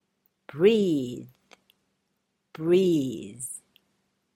またこの有声音” th “は、よく” z ” の発音と混同されます。
例えば、” breathe ” ＝「呼吸する」   ” breeze ” ＝「そよ風」が良い例です。
breathe.mp3